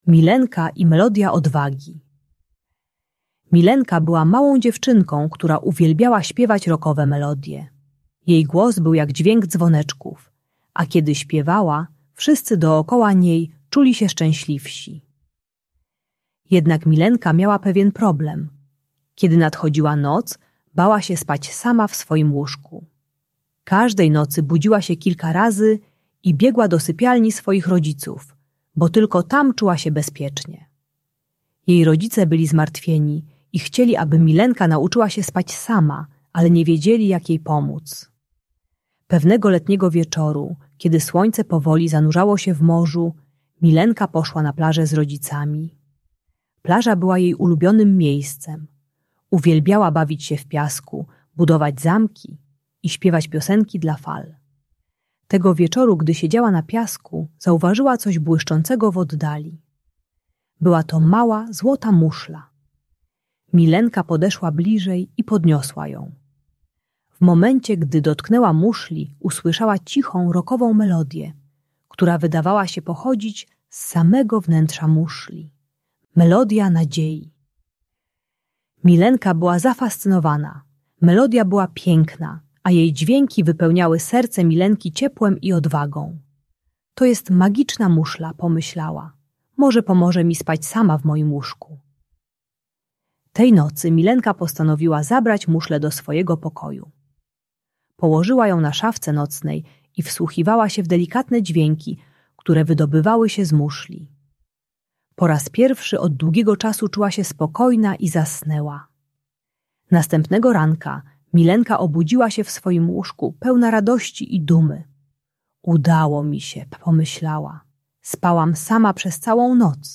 Milenka i Melodia Odwagi: Bajka o odwadze - Usypianie | Audiobajka